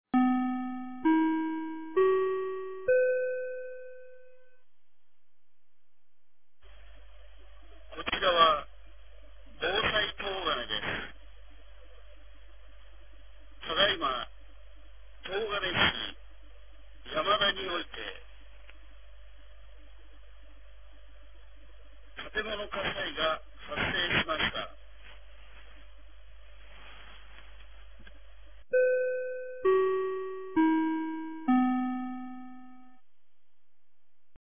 2024年12月22日 15時49分に、東金市より防災行政無線の放送を行いました。